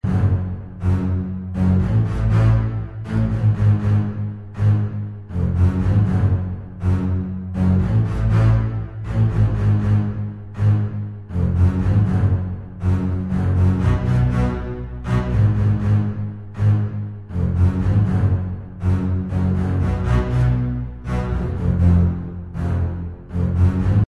Серьезная мелодия на контрабасе в разгар боя